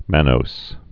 (mănōs)